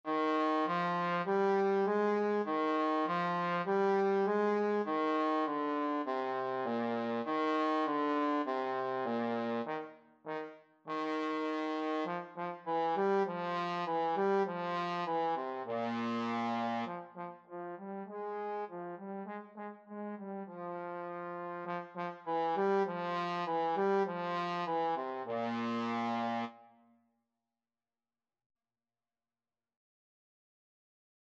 4/4 (View more 4/4 Music)
Trombone  (View more Beginners Trombone Music)
Classical (View more Classical Trombone Music)